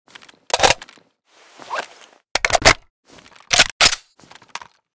famas_reload_empty.ogg.bak